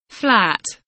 flat kelimesinin anlamı, resimli anlatımı ve sesli okunuşu